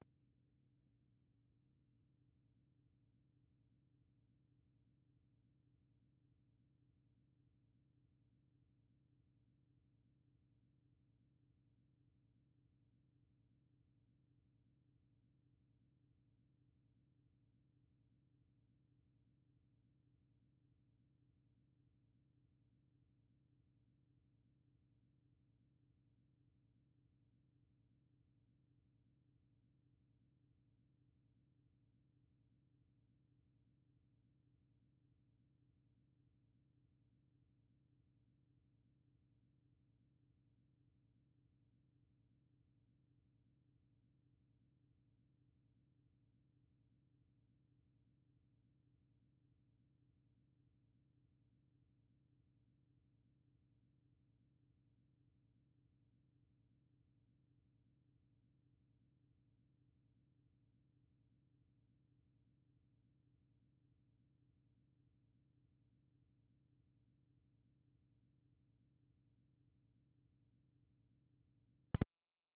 音声は入れてありません。